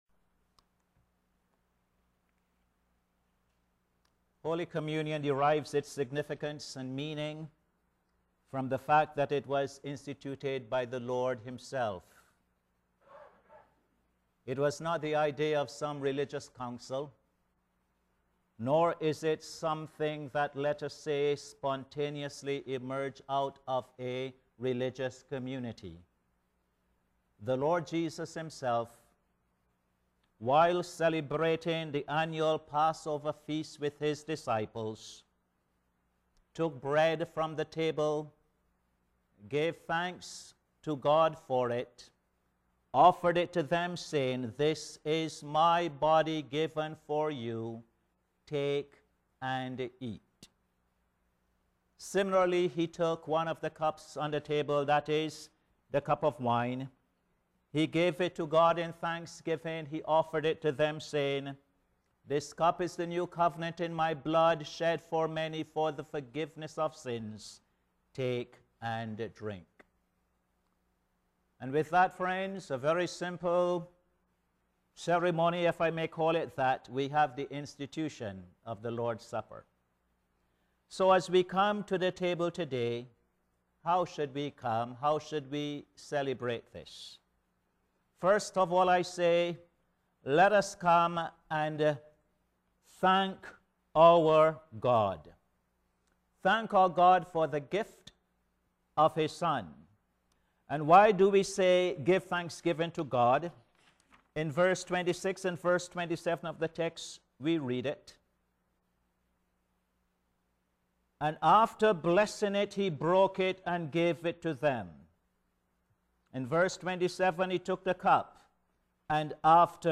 Posted in Sermons on 08.